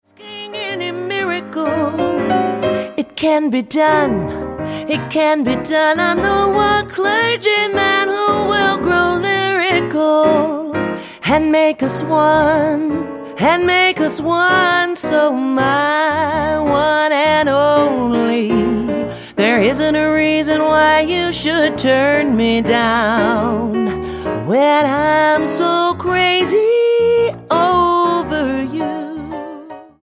NOTE: Background Tracks 1 Thru 10